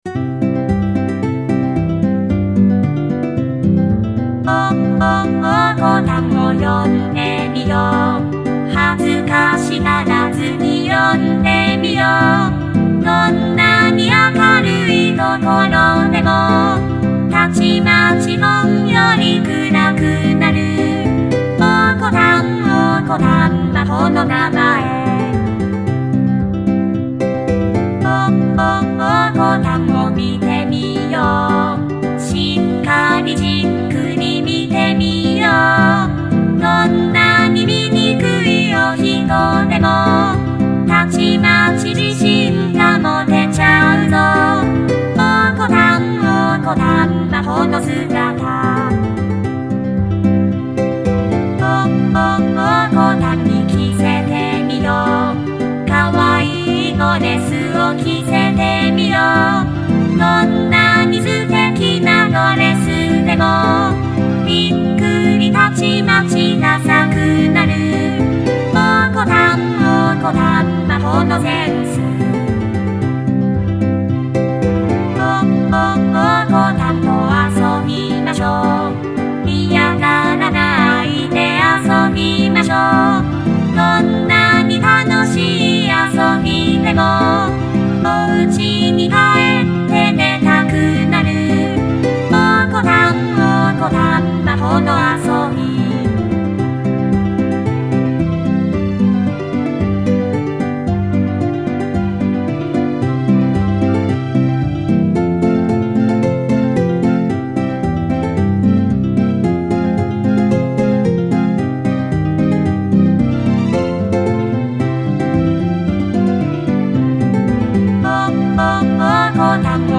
そういえば、温泉コンサート (1月25日) では、こんな歌も披露されましたね。